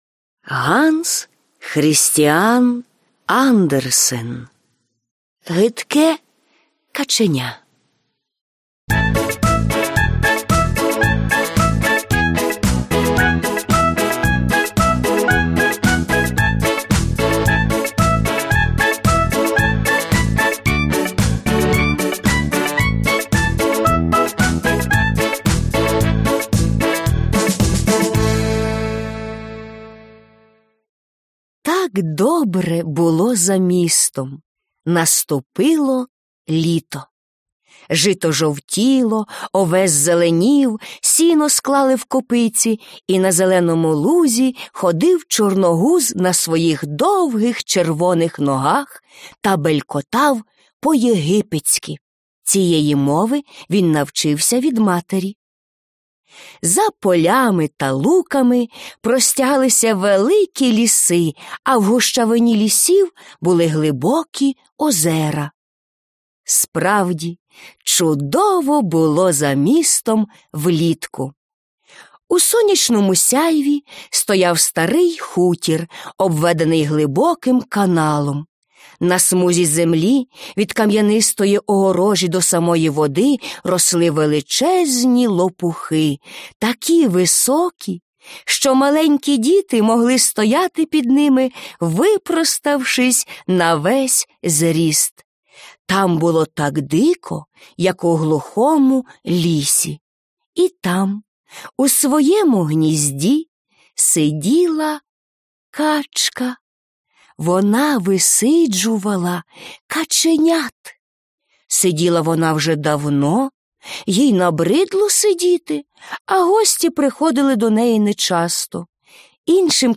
Аудіоказка Гидке каченя